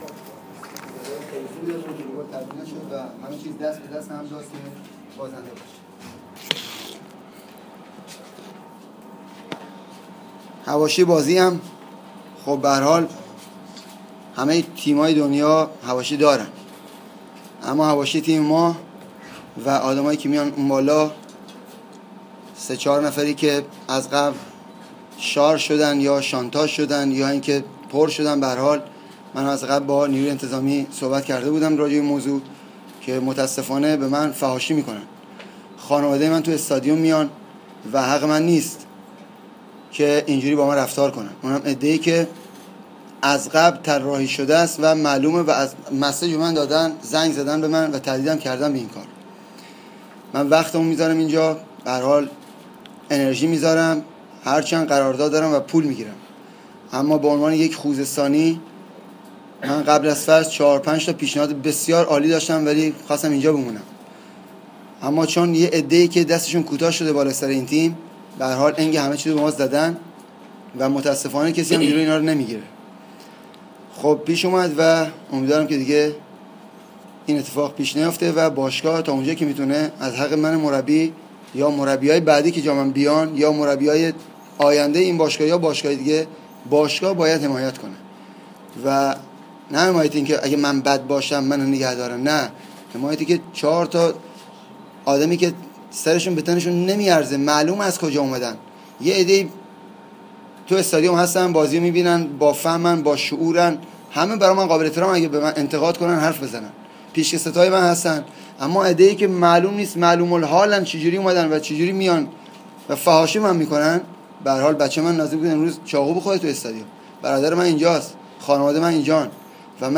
تکمیلی؛ کنفرانس خبری فولاد خوزستان - استقلال خوزستان؛ دلال ها امانم را بریده اند؛ برای پسرم چاقو کشیدند!